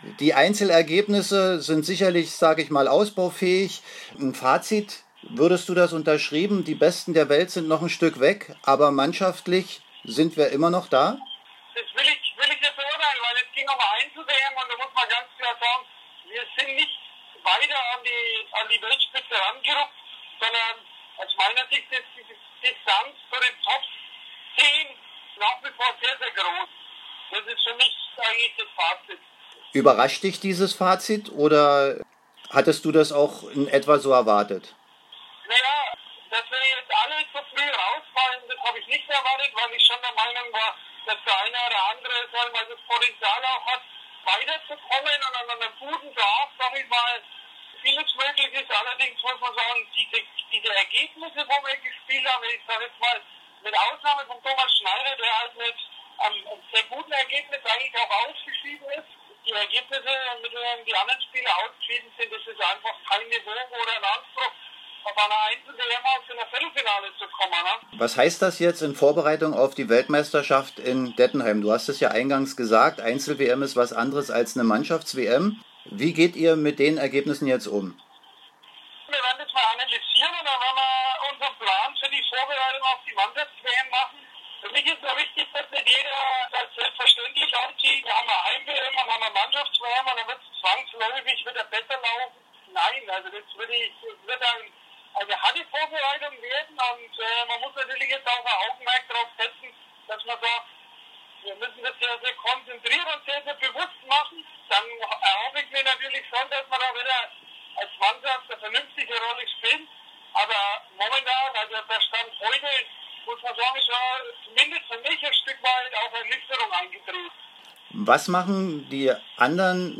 Während der VI. Einzel-WM sprachen wir im WM-Studio mit dem